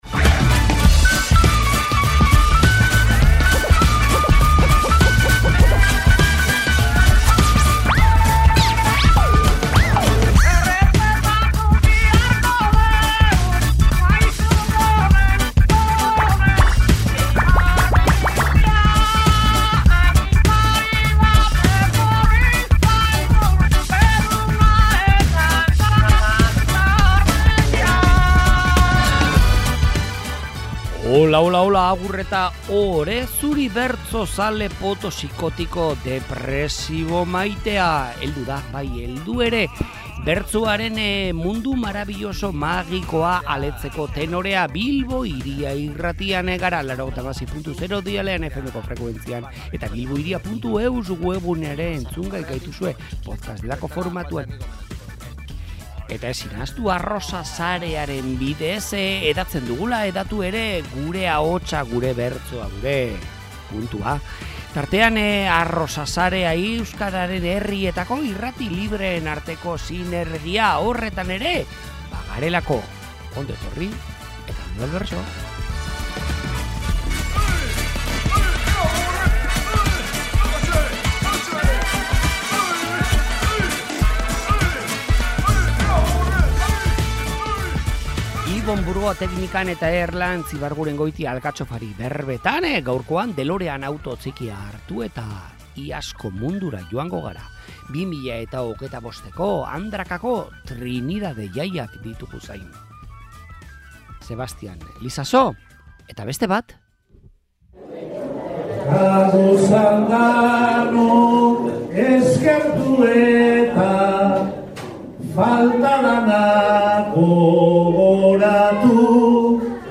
Bertso-afarian izandako ariketen aukeraketa diktatoriala duzue entzungai